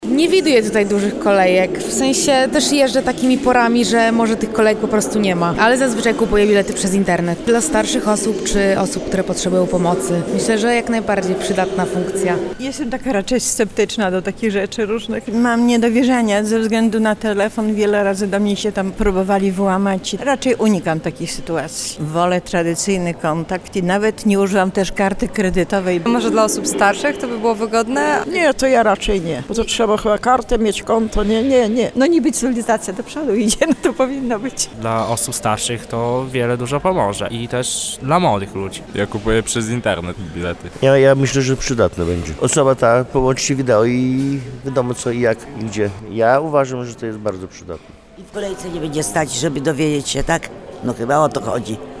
03-Sonda-videomat-1.mp3